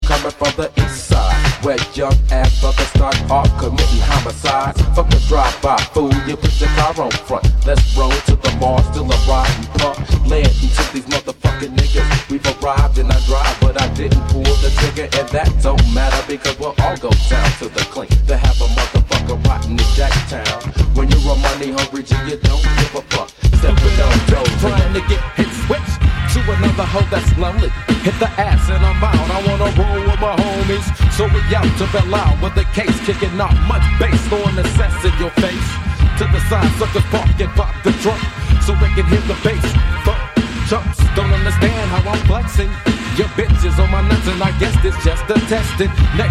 g-funk/gangsta rap